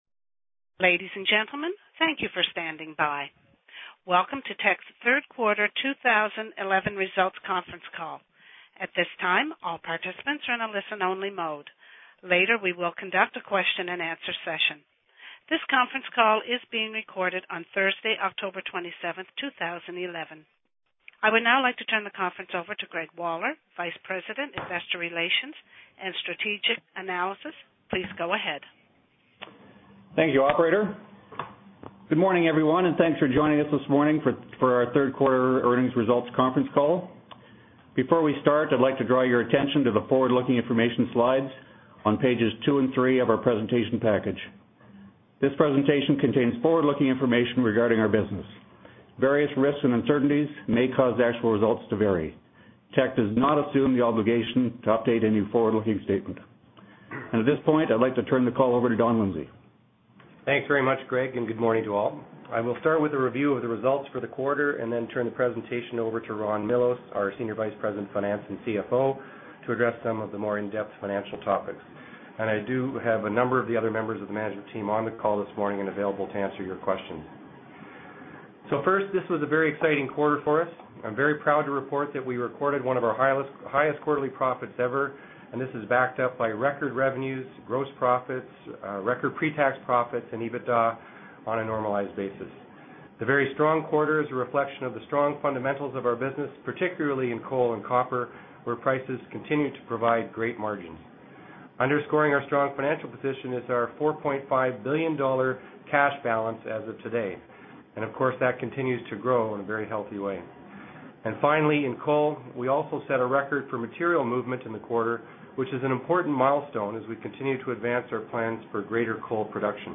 Q3 2011 Financial Report Conference Call Audio File